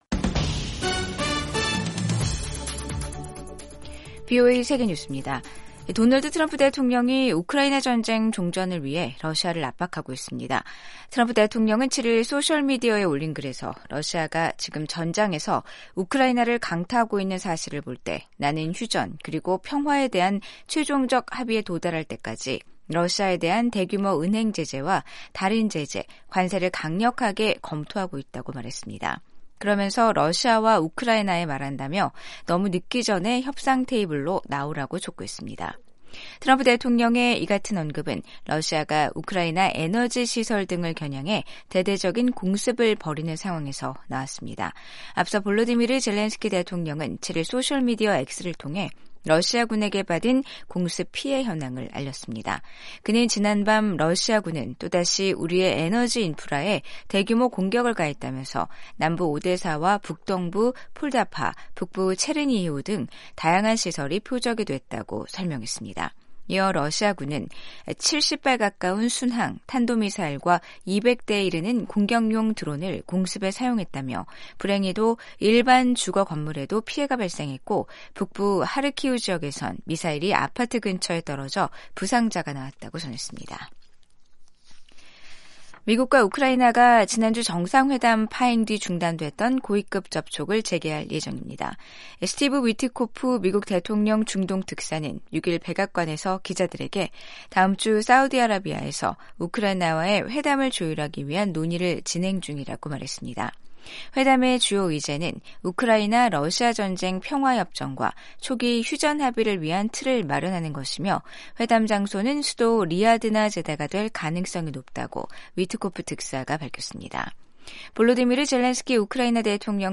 생방송 여기는 워싱턴입니다 2025/3/8 아침